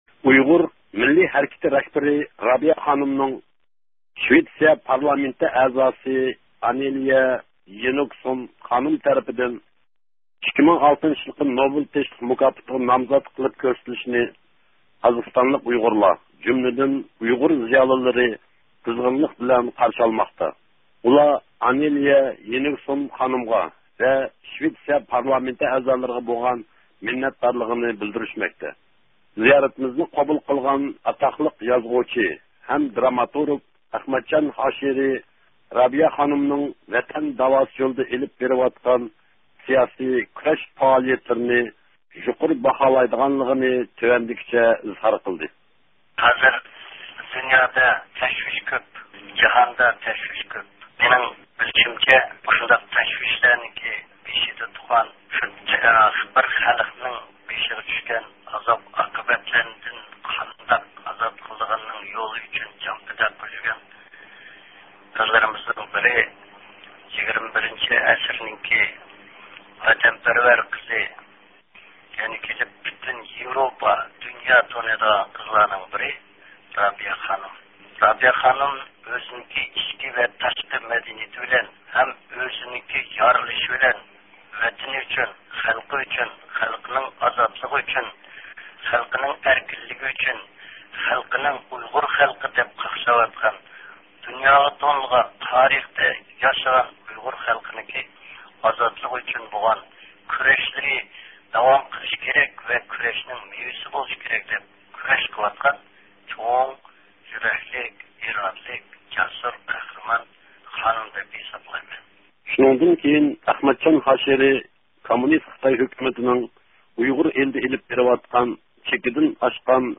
قازاقىستاندىكى ئۇيغۇر زىيالىلىرىنىڭ بۇ ھەقتىكى ئىنئىكاسلىرىنى